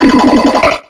Cri de Charmina dans Pokémon X et Y.